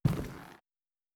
plastic5.wav